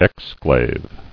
[ex·clave]